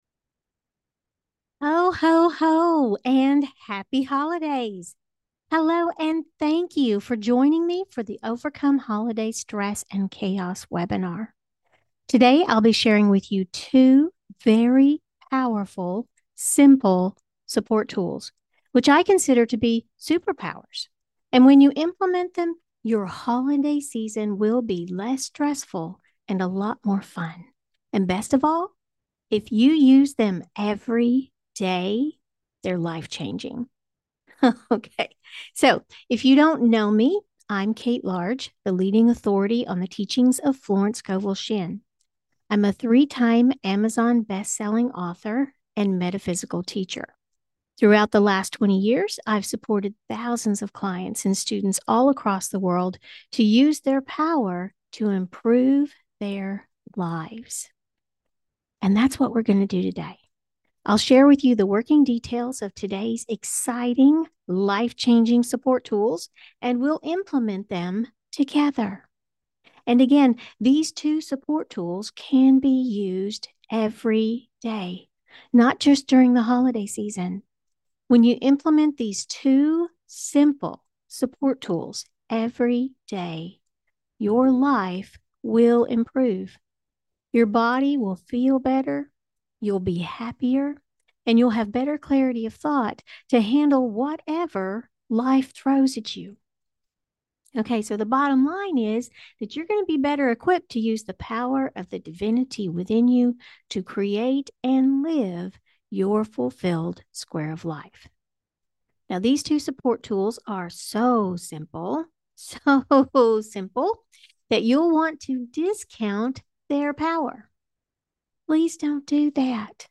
Click here to download the recording of the webinar. 39:07 minutes